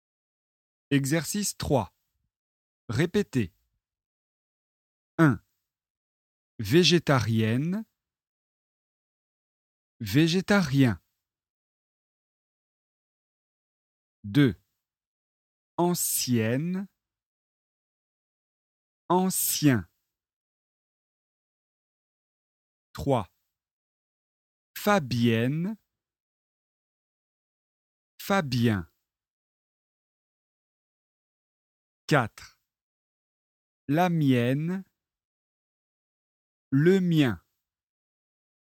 Leçon de phonétique et exercices de prononciation sur les voyelles nasales
⚠ ATTENTION : on ne prononce pas le N final de la voyelle nasale.
🔷 Exercice 2 : répétez